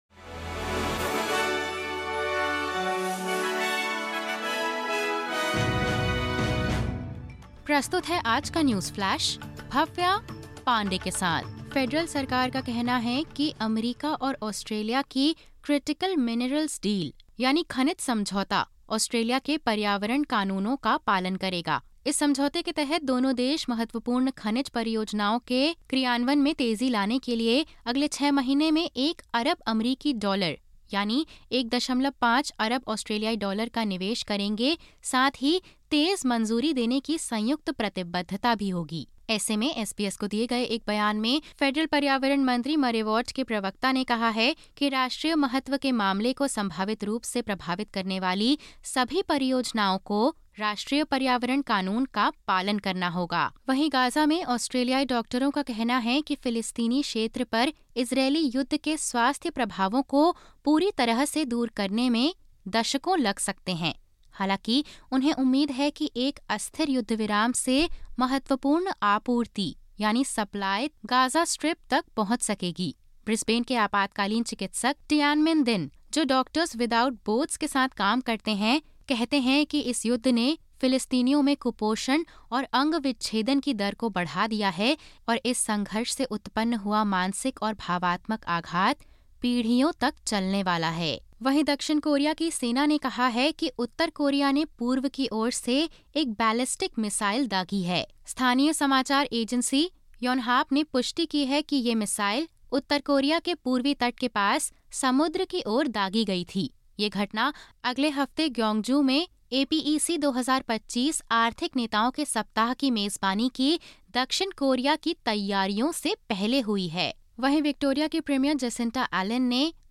ऑस्ट्रेलिया समेत अन्य देशों से 22/10/25 के प्रमुख समाचार हिंदी में सुनें।